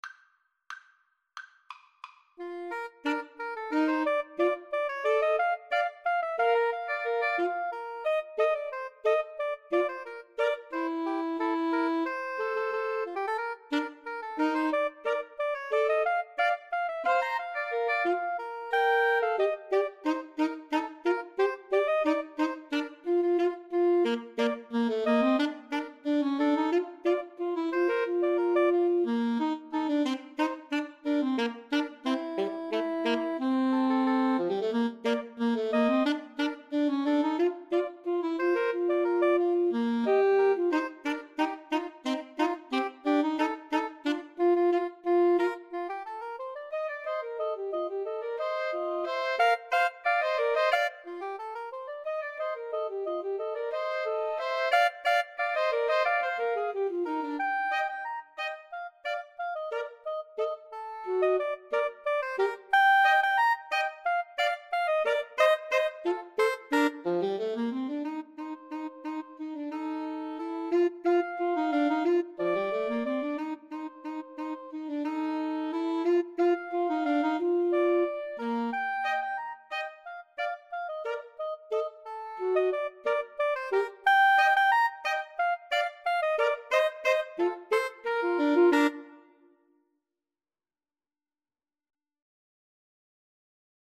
Bb major (Sounding Pitch) (View more Bb major Music for Woodwind Trio )